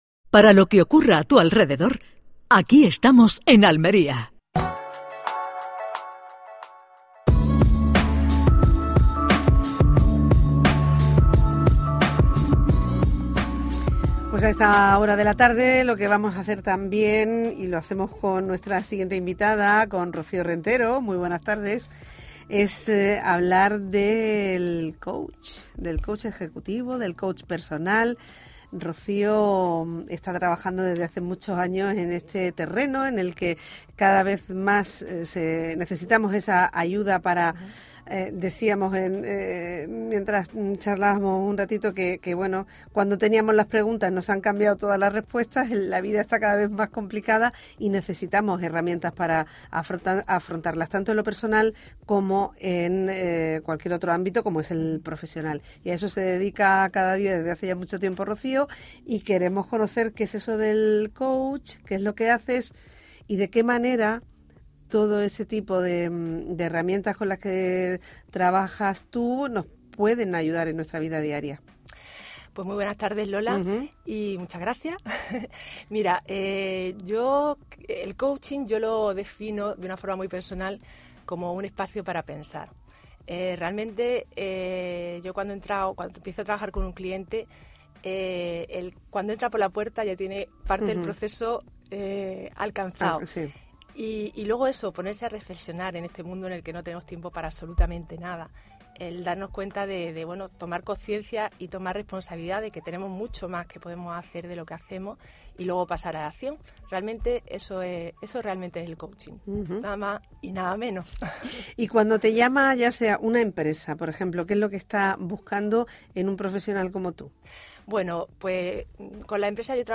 Entrevista en Canal Sur Radio
Entrevista en Canal Sur Radio hablando sobre Coaching y nuestro próximo Programa de Crecimiento Personal en una Sociedad Cambiante.